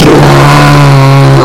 令人毛骨悚然的气息 NEW
描述：令人毛骨悚然的呼吸可用于恐怖游戏，电影等。
标签： 怪物 恐怖 吓人
声道立体声